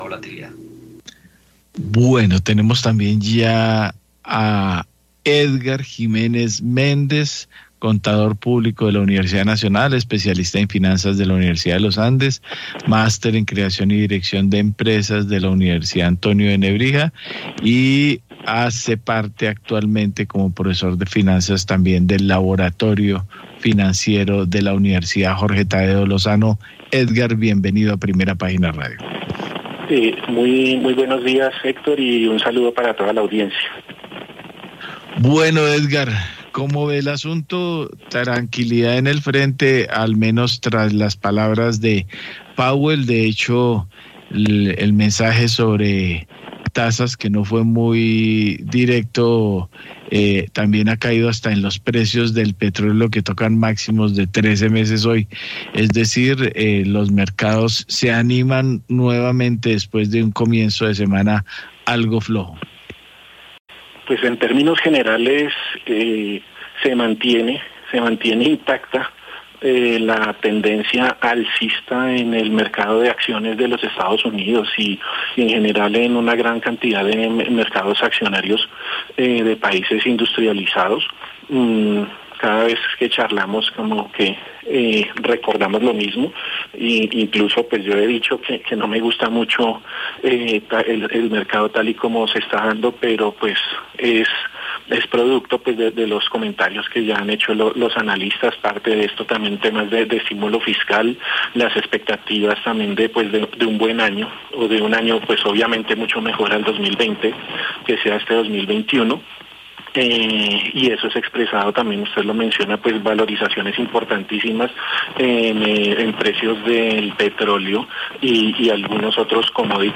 Escuche la entrevista en Javeriana Estéreo